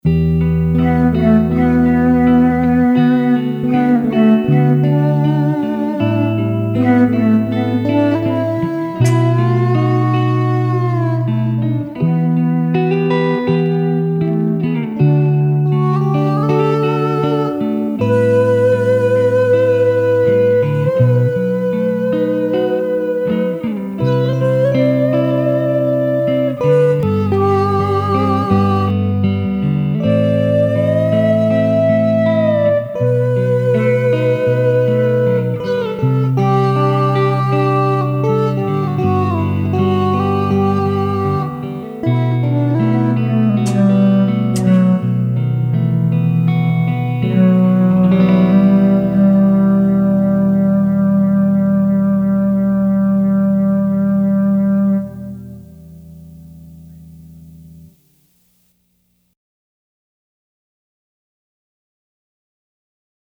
In this next clip, I’m running only the MIDI out from the guitar into the synth, then into my DAW. I recorded two separate tracks: One for the guitar, then I overdubbed a “bamboo flute” patch over the guitar.